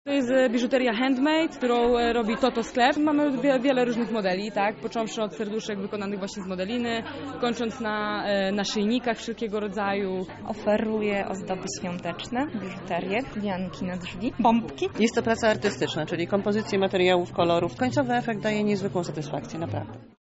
O tym co można było znaleźć w kawiarni mówią sami wytwórcy.